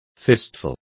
Complete with pronunciation of the translation of fistful.